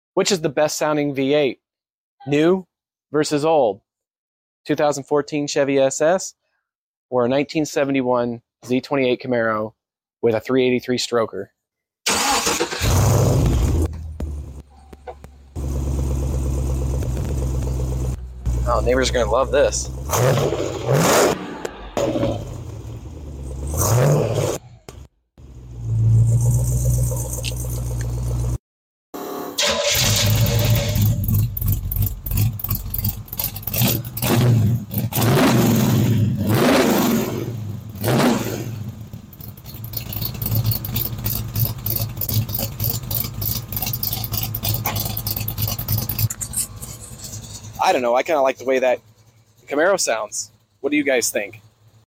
BEST SOUNDING GM V8?